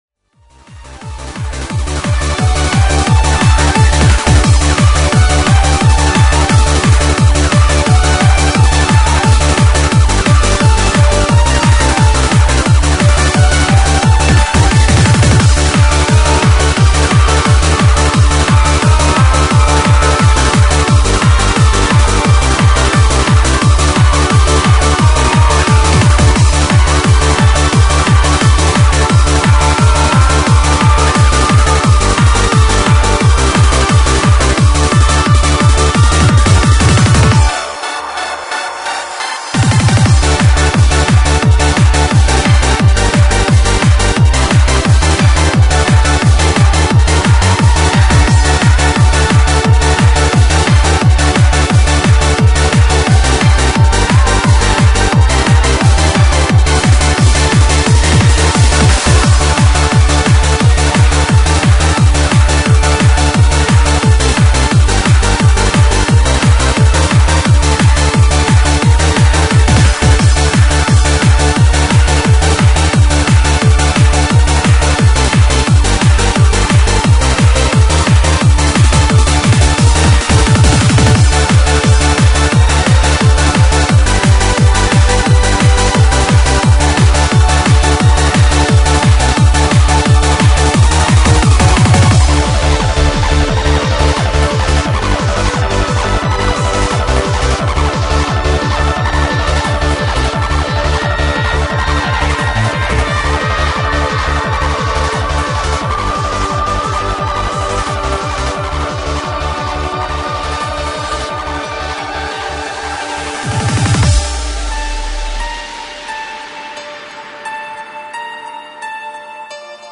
Freeform